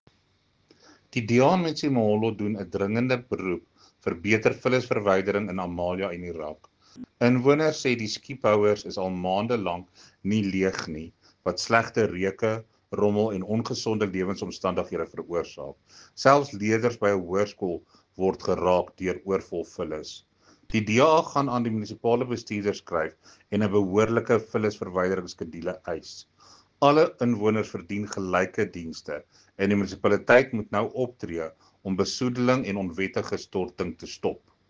Afrikaans soundbite by Cllr Jacques Barnard.